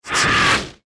File:Skeetera roar.mp3
Skeetera_roar.mp3